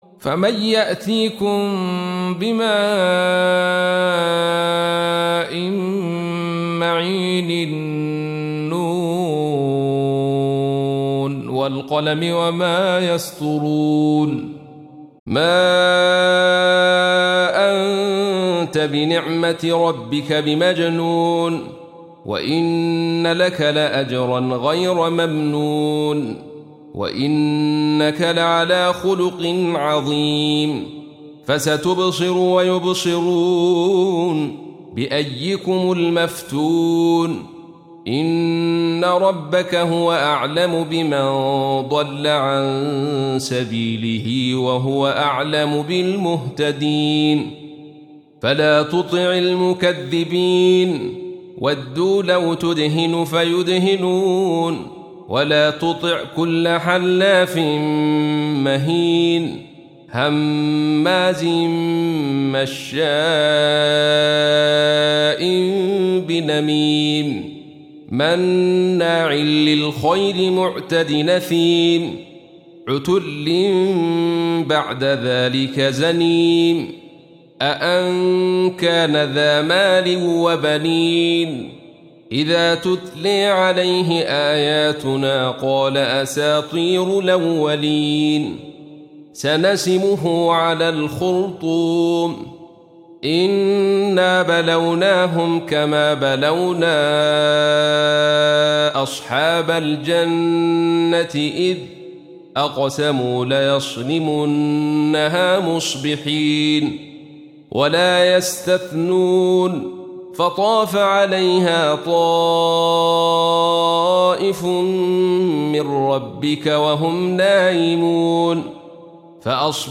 68. Surah Al-Qalam سورة القلم Audio Quran Tarteel Recitation
Surah Sequence تتابع السورة Download Surah حمّل السورة Reciting Murattalah Audio for 68.